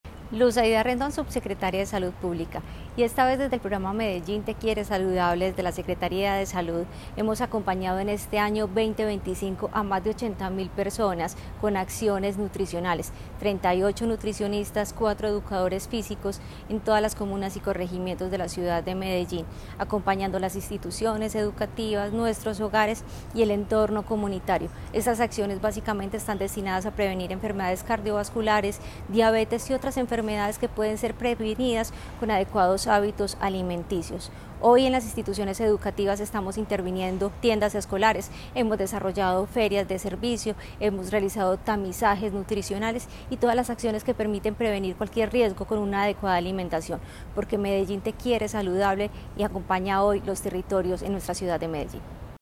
Declaraciones de la subsecretaria de Salud Pública, Luz Aída Rendón.
Declaraciones-de-la-subsecretaria-de-Salud-Publica-Luz-Aida-Rendon..mp3